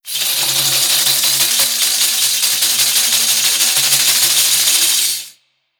Danza árabe, agitar un pandero
continuo
moneda
Sonidos: Acciones humanas